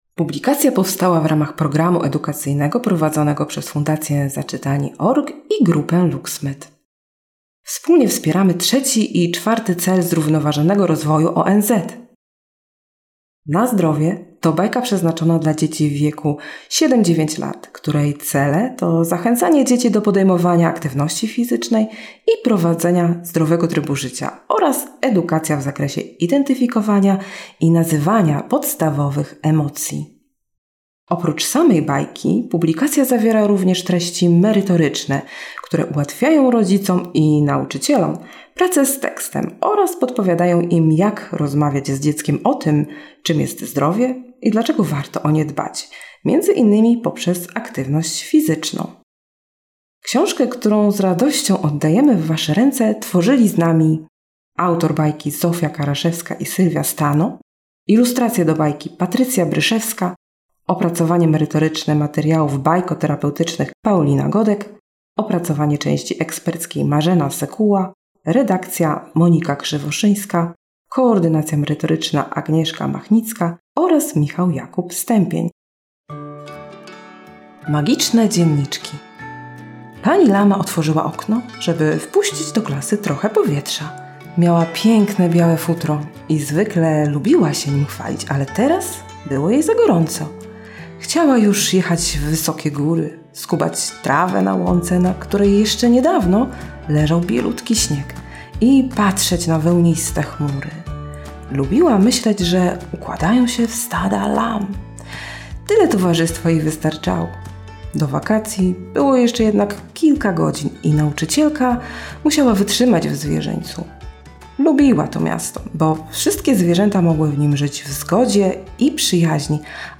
Na-Zdrowie-audiobook.mp3